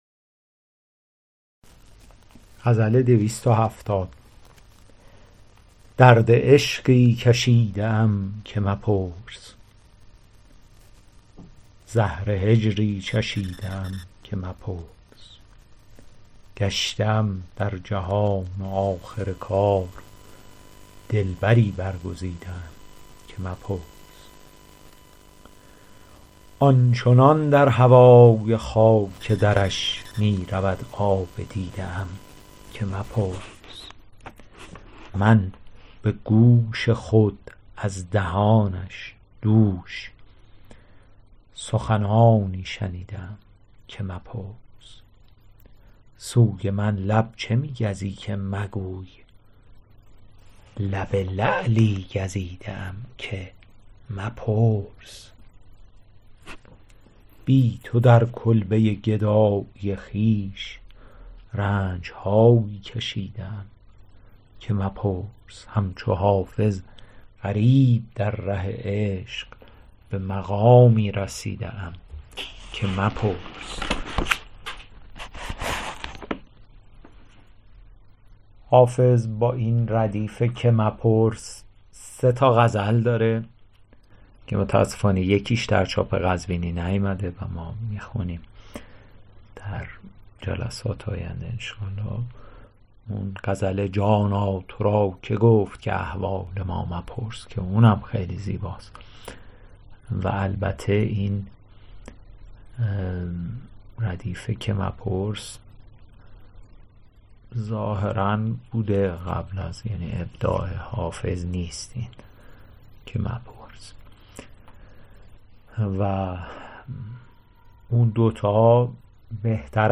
شرح صوتی غزل شمارهٔ ۲۷۰